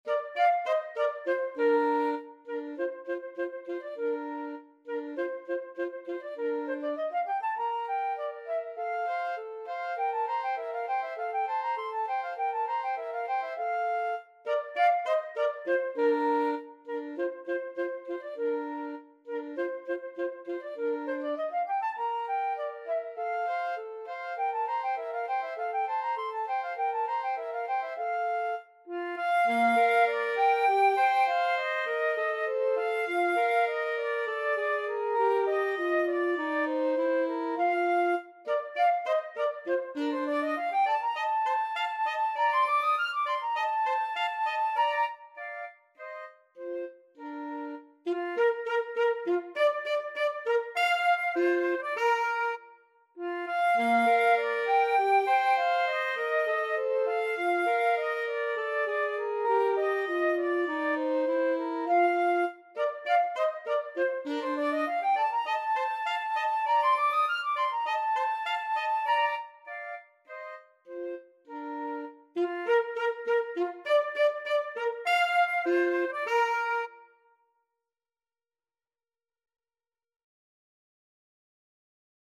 Free Sheet music for Flute-Saxophone Duet
Bb major (Sounding Pitch) (View more Bb major Music for Flute-Saxophone Duet )
2/4 (View more 2/4 Music)
Classical (View more Classical Flute-Saxophone Duet Music)